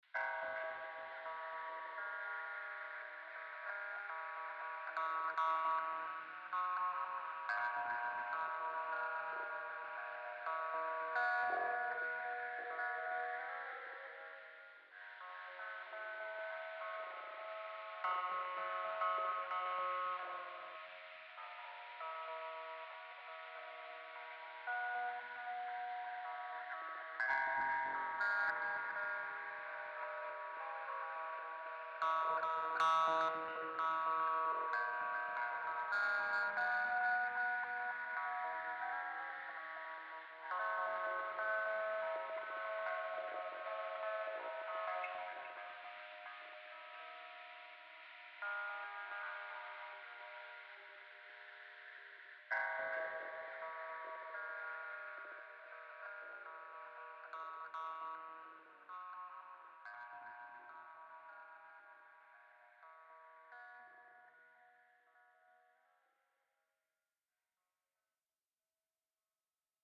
more dramatically flavored ambient drones
Stereophonic headphones strongly recommended :).»